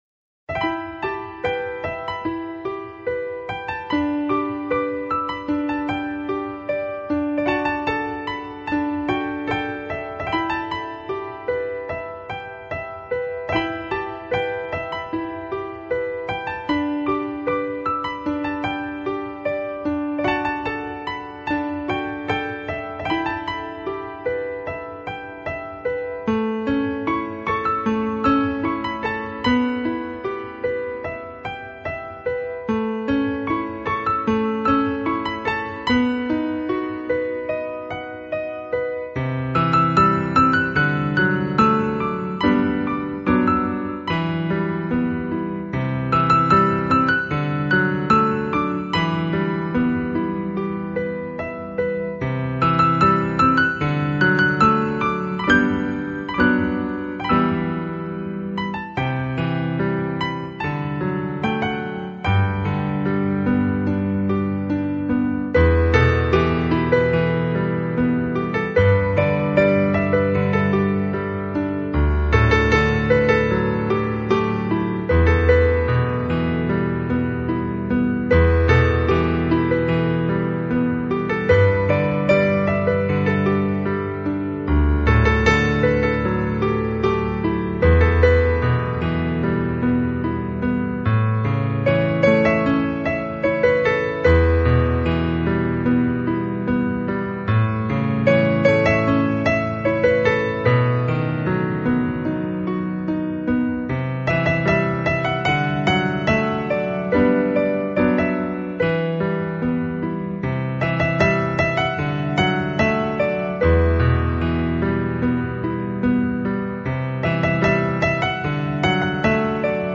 Solopiano